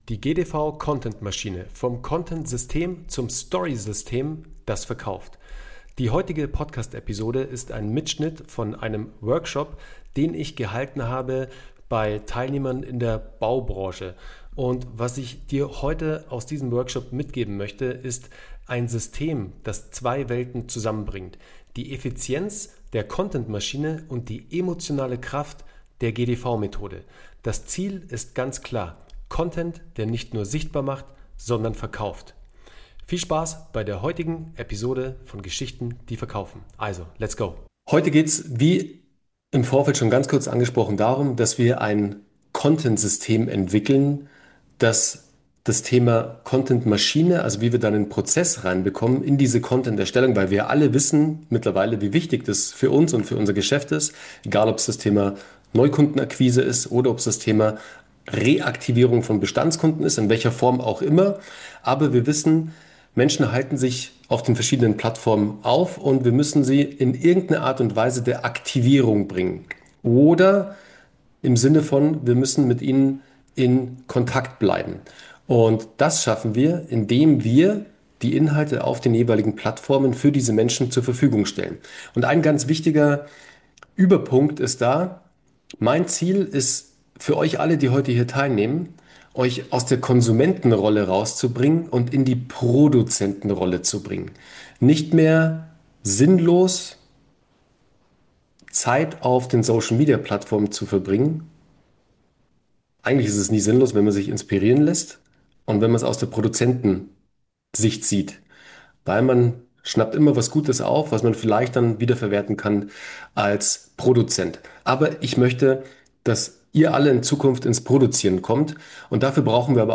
In dieser Podcast-Folge hörst du einen Mitschnitt aus einem Workshop mit Unternehmern aus der Baubranche und ein System, das genau dieses Problem löst. Ich zeige dir, warum klassische Content-Maschinen zwar effizient sind, aber emotional leer bleiben und wie du sie mit der GDV-Methode in ein Story-System verwandelst, das Vertrauen aufbaut, Widerstand senkt und Verkäufe vorbereitet.